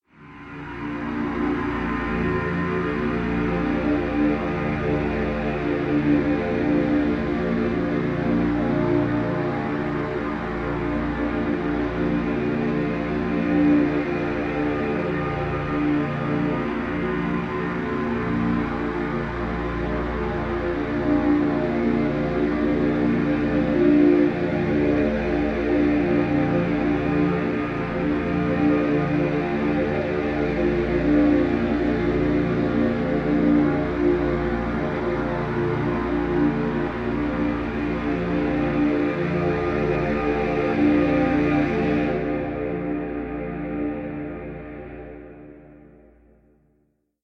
Звуки киберпанка
Шум цифрового мегаполиса